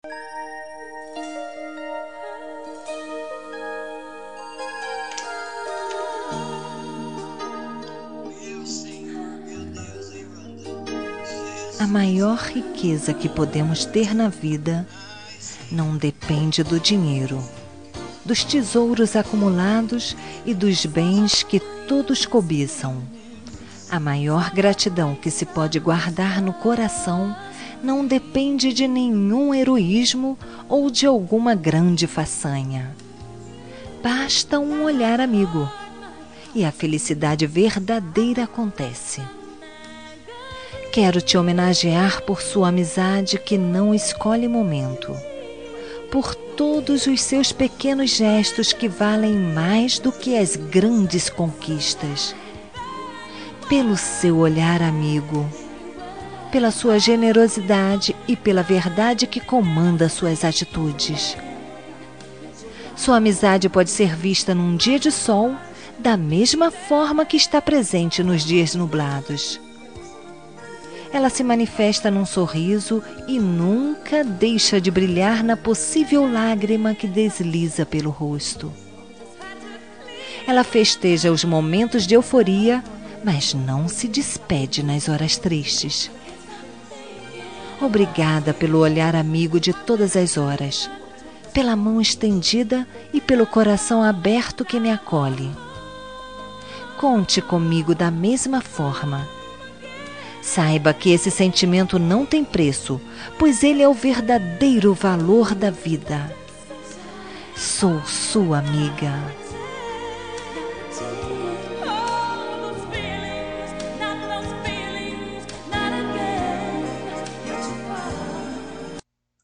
Telemensagem de Amizade – Voz Feminina – Cód: 99